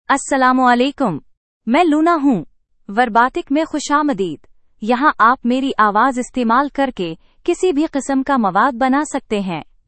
Luna — Female Urdu (India) AI Voice | TTS, Voice Cloning & Video | Verbatik AI
Luna is a female AI voice for Urdu (India).
Voice sample
Listen to Luna's female Urdu voice.
Female
Luna delivers clear pronunciation with authentic India Urdu intonation, making your content sound professionally produced.